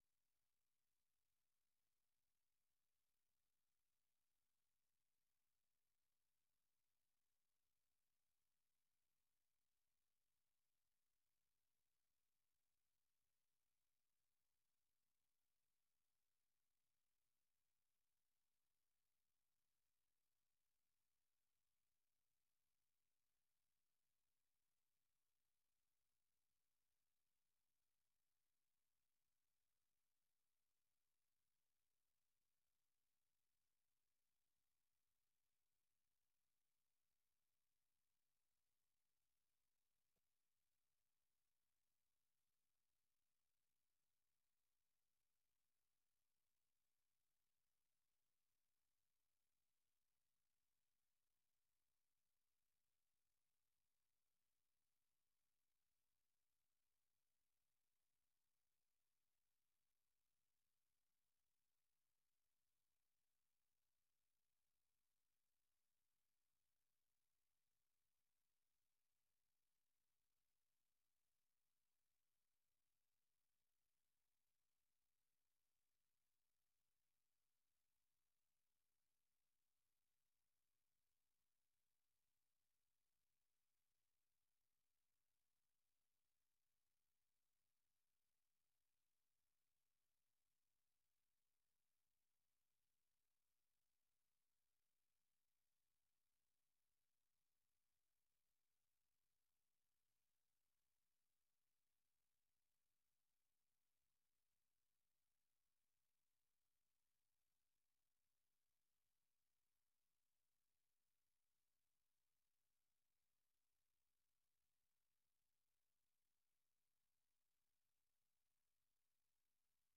དགོང་དྲོའི་རླུང་འཕྲིན།